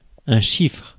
Ääntäminen
US Tuntematon aksentti: IPA : /ˈnjuːməɹəl/ IPA : /ˈnumɝəl/